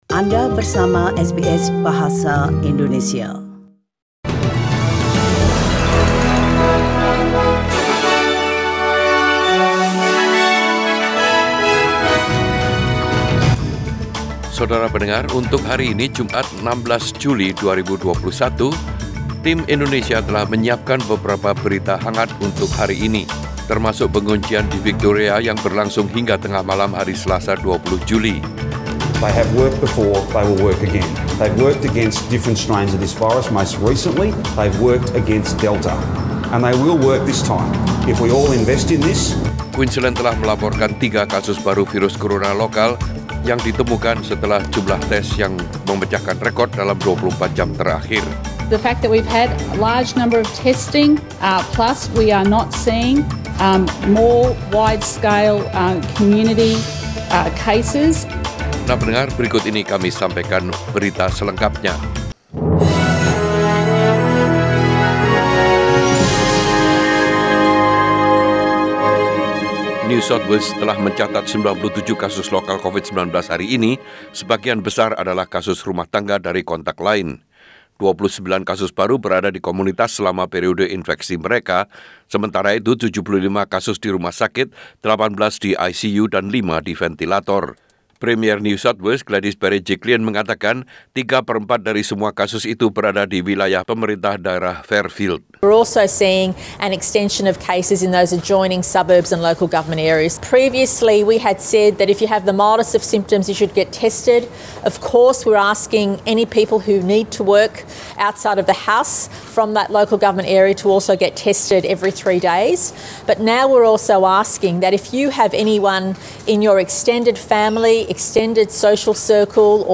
Warta Berita Radio SBS dalam Bahasa Indonesia Source: SBS